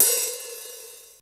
HR16B HHOP 2.wav